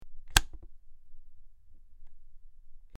SwitchClicksOnOff PE447604
Switch; Clicks On And Off.